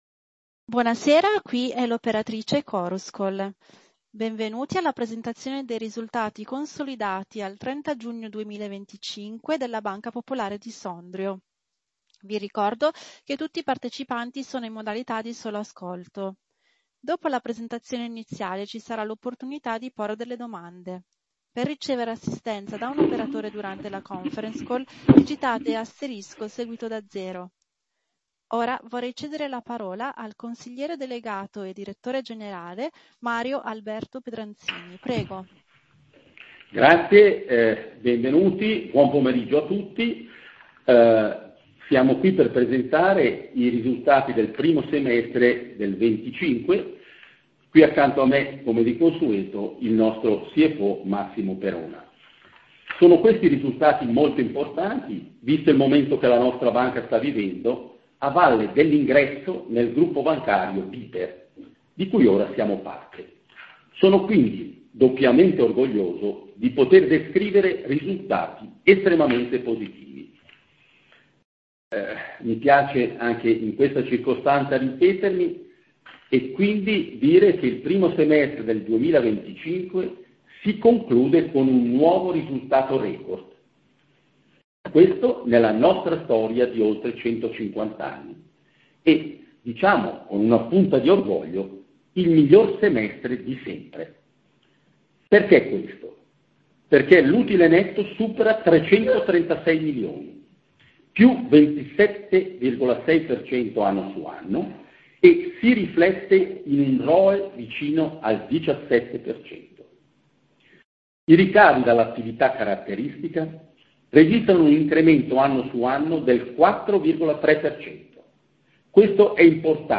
1H Conference call